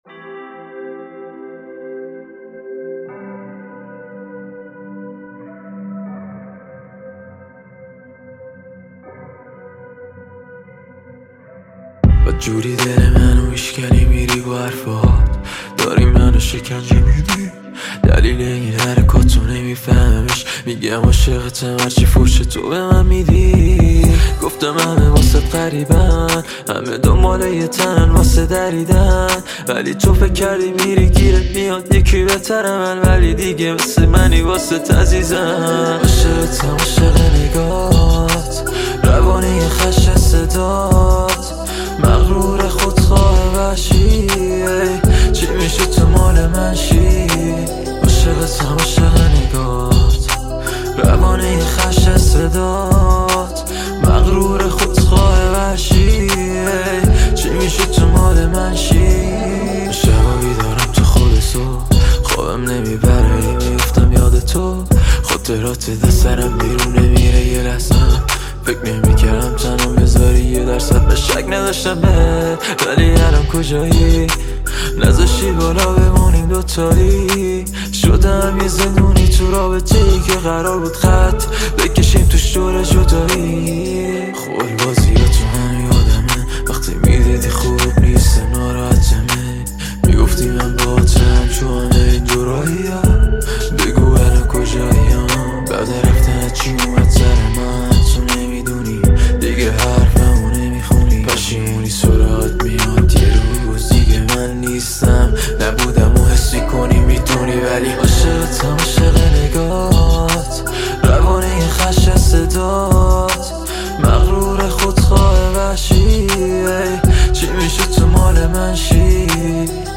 ترانه بسیار زیبا شنیدنی احساسی عاشقانه